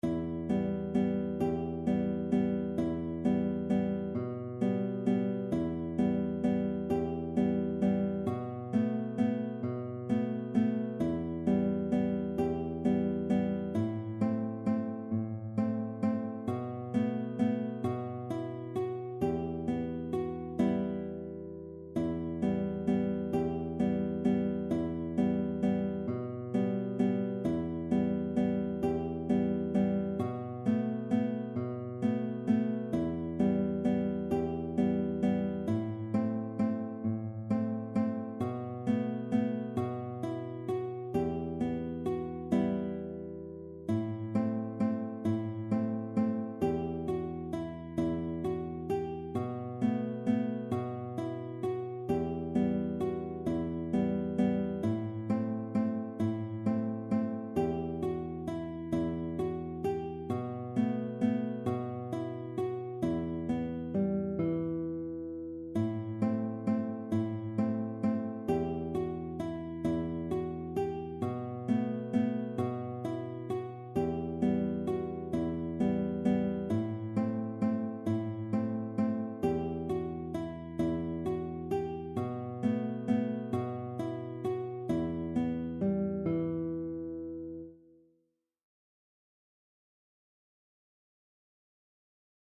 Spielstücke für Gitarre im Walzertakt